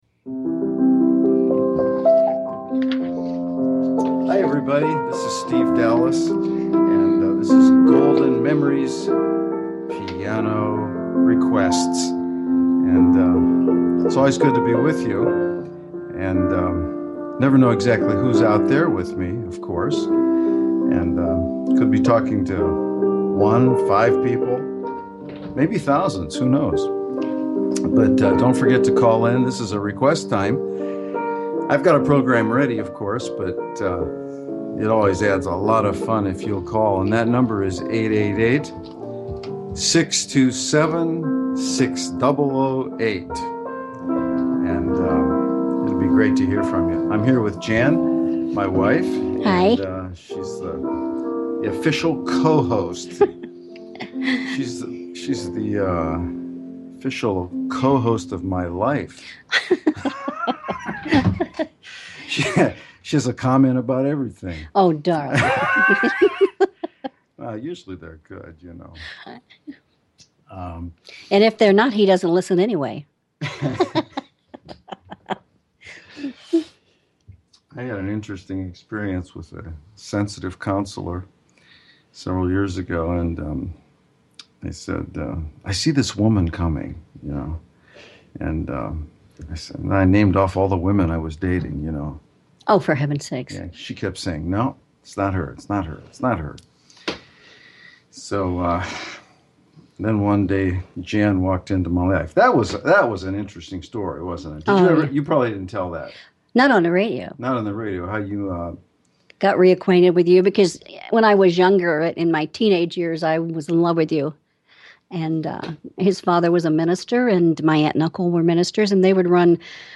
singing together all the wonderful oldies
And certainly give me a call with your song request (see listing of available numbers) and we’ll see if I can play it on the spot!